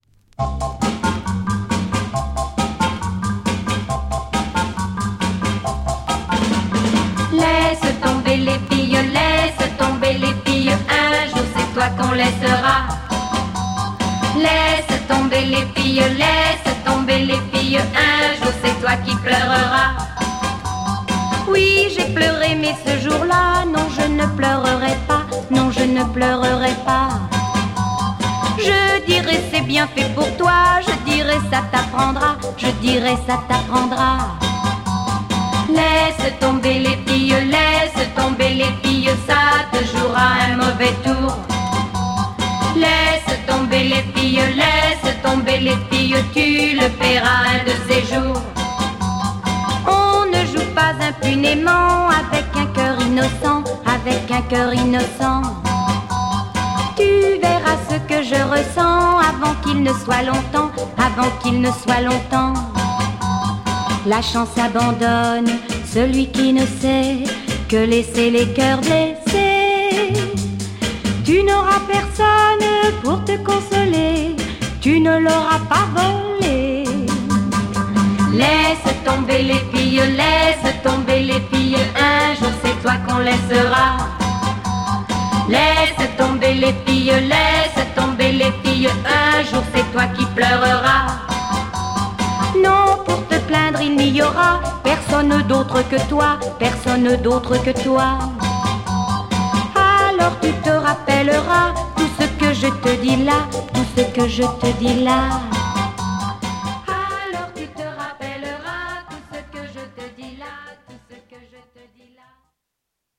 Rare alternative version